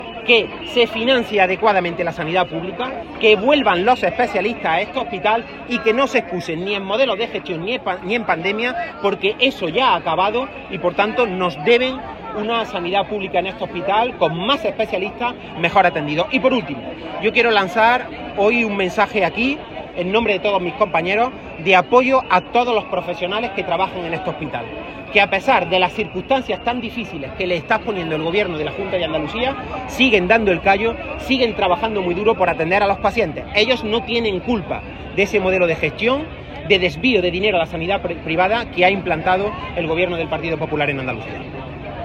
Concentración de protesta contra la Junta por el desmantelamiento del centro hospitalario
El responsable socialista ha hecho estas declaraciones en la concentración de protesta celebrada junto al centro hospitalario en la que ha participado en su doble condición de alcalde de Arjona y secretario general del PSOE de Jaén.
Cortes de sonido